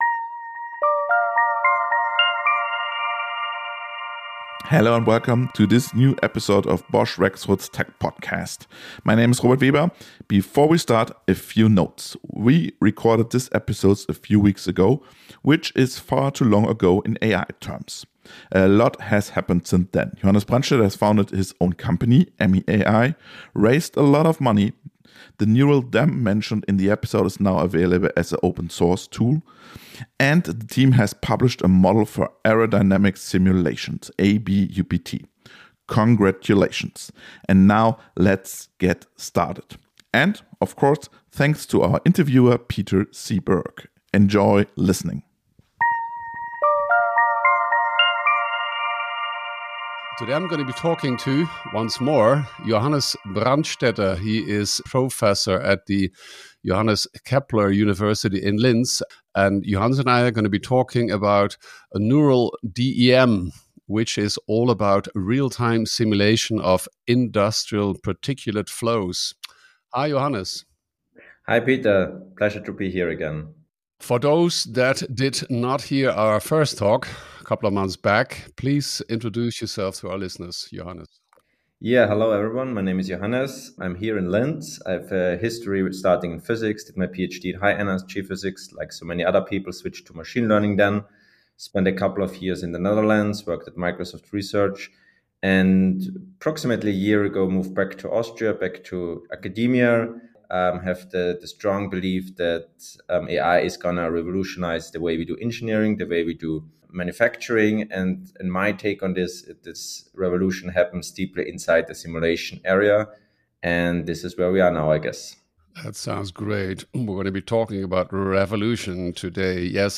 A conversation about science, visions, and the potential to rethink entire industries.